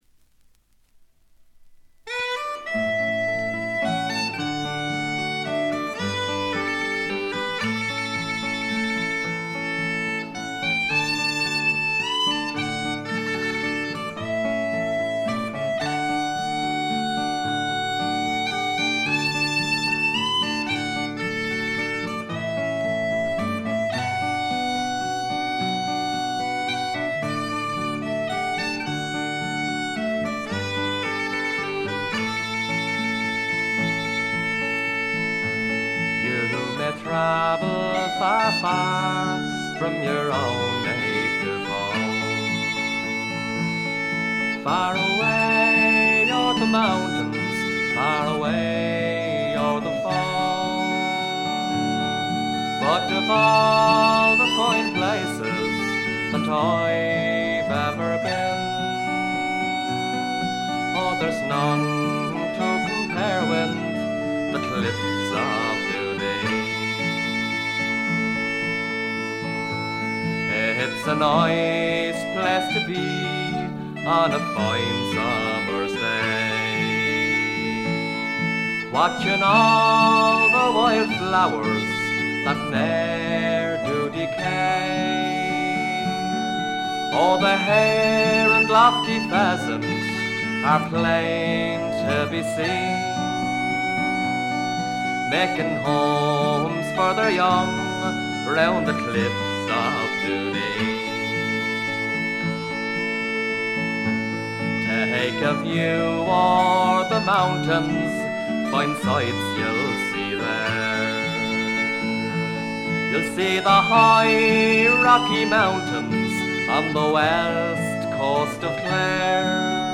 ほとんどノイズ感無し。
アイリッシュ・フォーク基本中の基本です。
中身は哀切なヴォイスが切々と迫る名盤。
試聴曲は現品からの取り込み音源です。